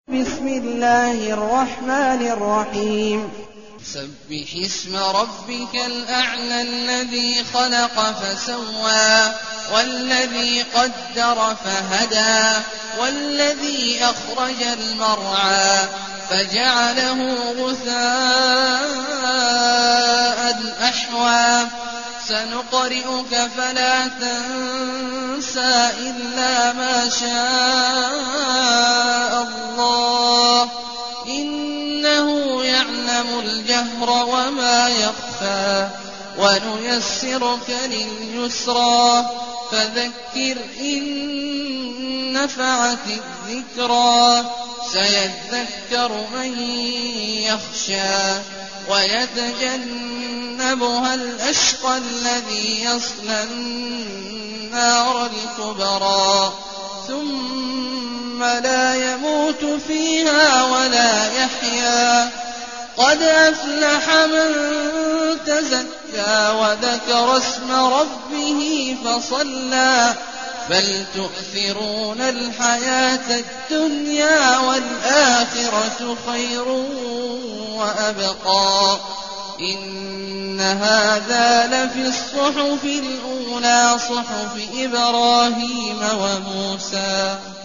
المكان: المسجد النبوي الشيخ: فضيلة الشيخ عبدالله الجهني فضيلة الشيخ عبدالله الجهني الأعلى